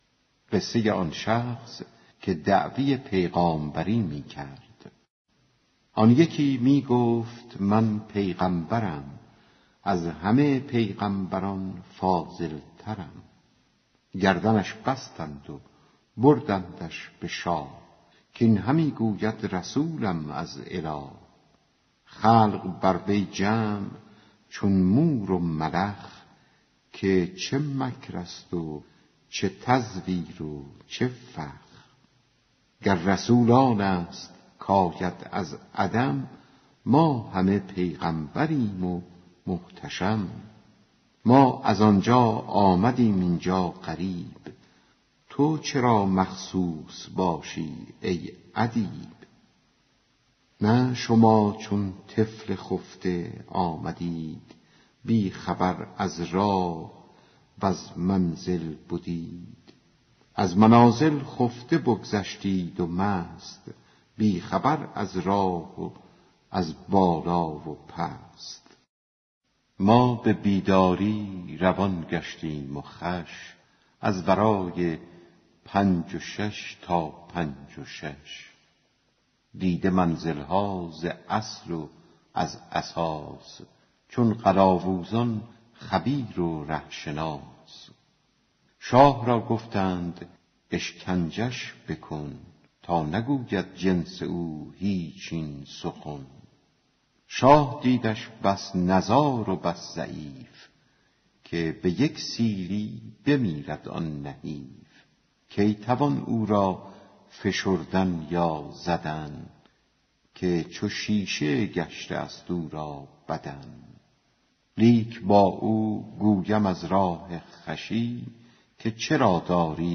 دکلمه دعوی پیامبری کردن آن مردِ لاغر و گرسنه